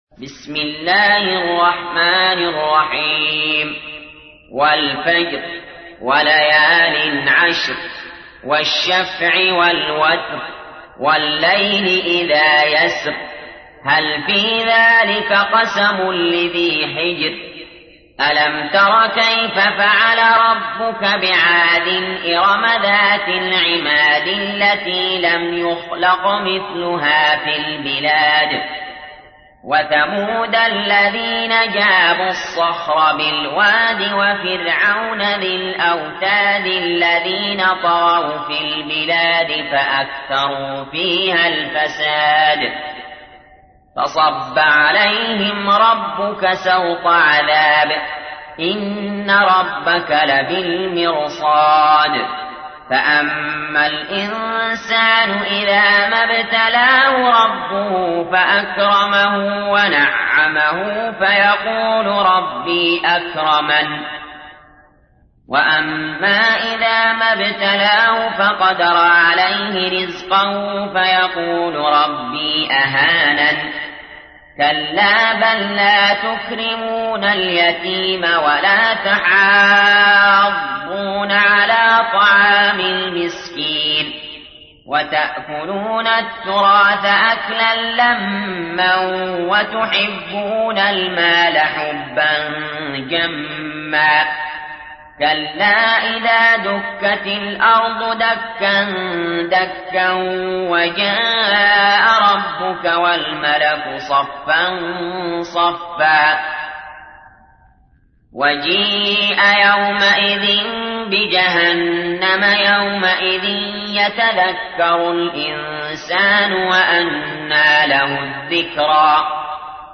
تحميل : 89. سورة الفجر / القارئ علي جابر / القرآن الكريم / موقع يا حسين